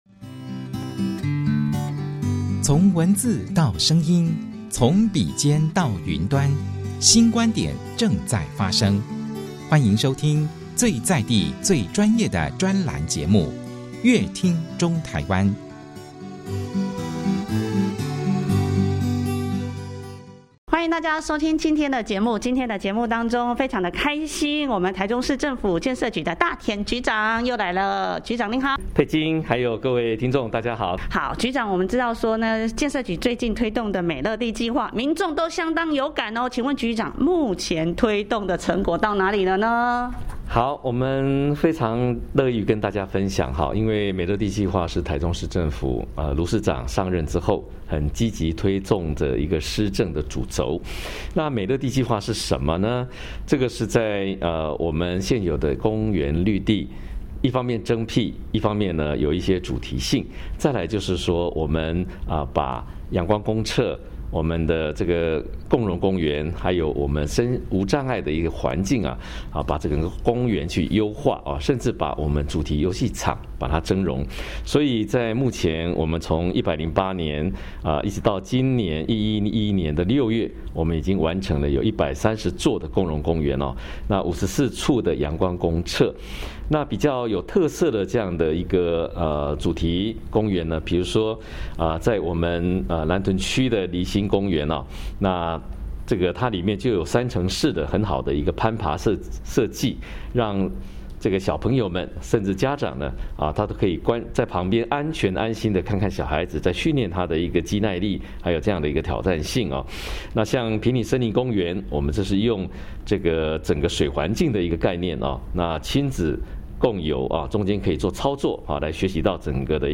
美樂地計畫 讓民眾超有感 建設局近年積極推動「美樂地計畫」，讓民眾相當有感，陳大田局長在節目中分享「美樂地計畫」推動成果，另外在台中馬卡龍公園號稱有全臺戶外最高溜滑梯，相當吸引人，到底它有甚麼迷人之處呢?以及建設局除了有共融公園外，也推動花之道計畫，節目中陳局長為大家一一解答。 建設局除了推動燙平專案、美樂地計畫等基礎建設外，對於重大建設的推動更是不遺餘力，尤其是重大道路建設，局長也在節目中細數還有其他聯外的交通建設正在規劃中，等待建設完成，民眾就可以享受便捷又便利的交通，讓宜居台中的美名更彰顯。